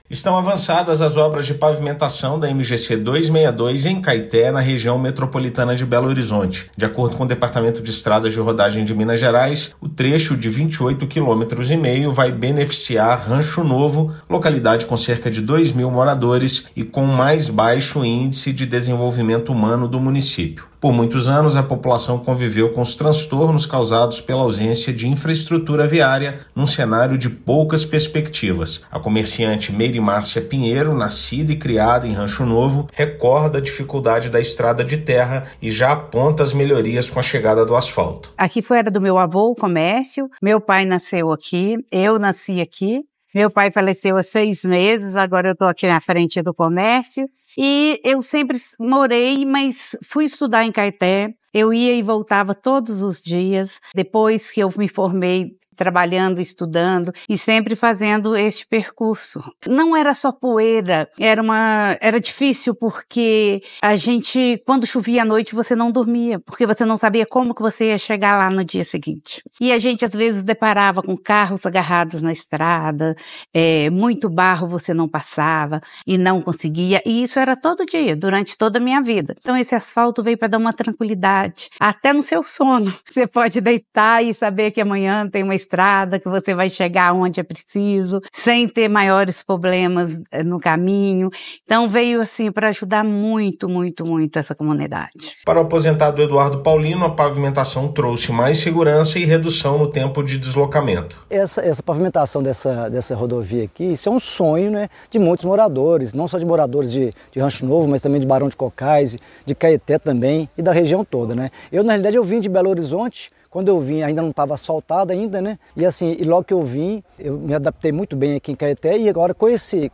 Trecho de 28,5 quilômetros até Barão de Cocais ganha melhorias e pode servir de alternativa de tráfego para quem circula pela BR-381. Ouça matéria de rádio.